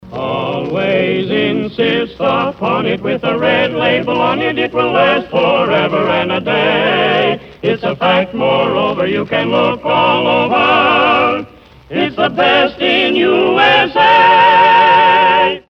Sample sound files from the radio program: